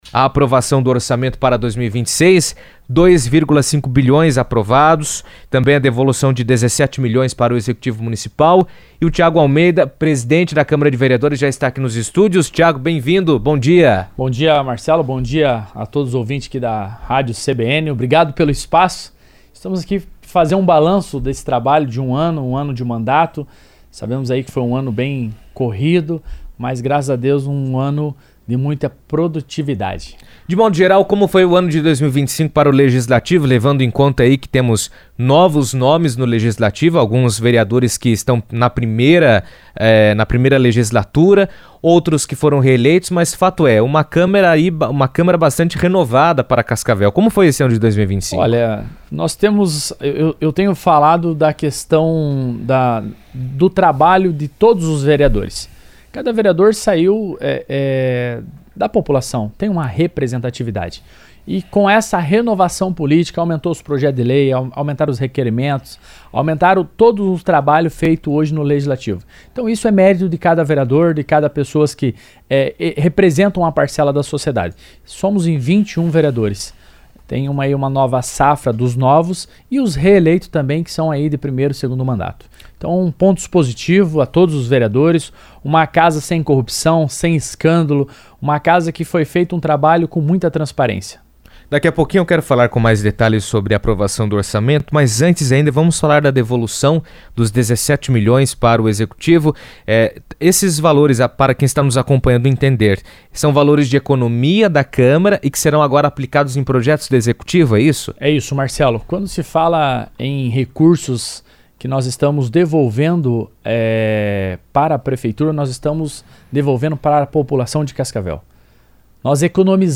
A Câmara de Vereadores de Cascavel devolveu R$ 17,1 milhões ao Executivo e aprovou o orçamento municipal de R$ 2,5 bilhões para 2026, definindo a distribuição de recursos para diferentes áreas da administração. Tiago Almeida, presidente da Câmara, comentou sobre os temas em entrevista à CBN, explicando os critérios adotados para a devolução dos recursos e os detalhes do orçamento aprovado.